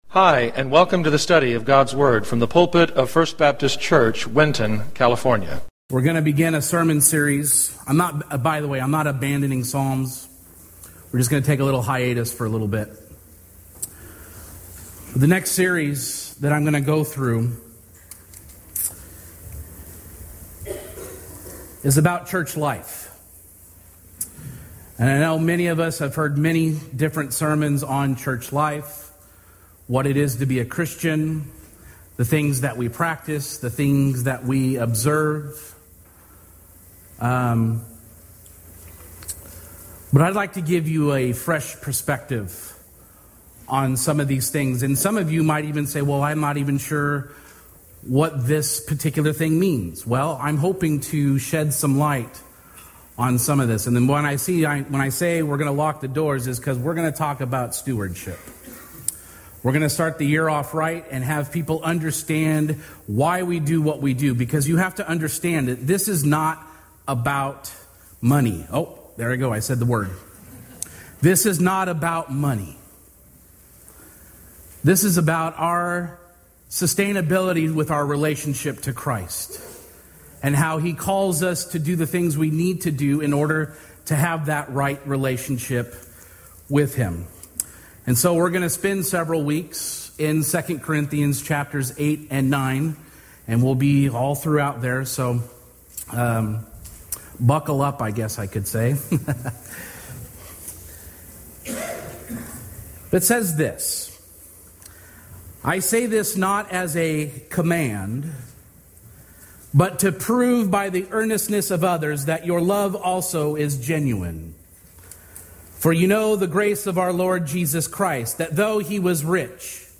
Sermons | Winton First Baptist Church